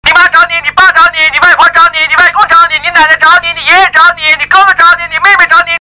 旋律动听悦耳